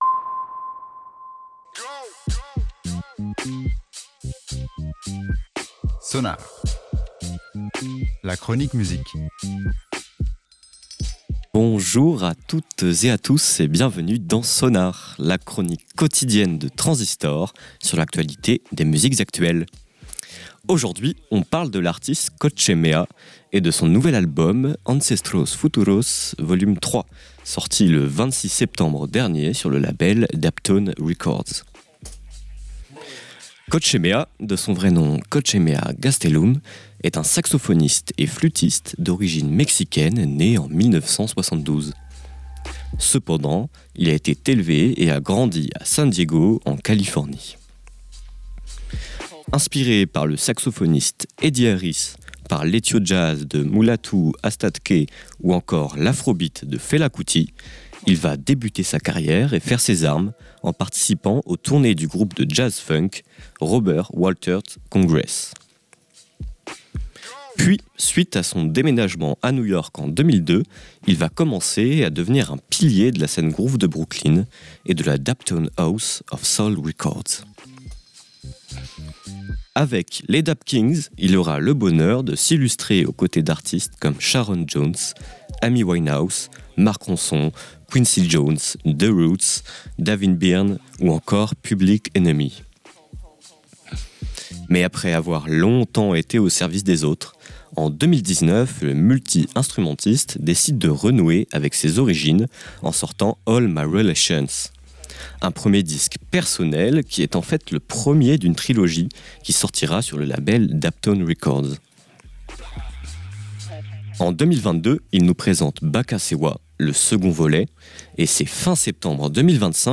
saxophoniste et flûtiste
le multi-instrumentiste décide de renouer avec ses origines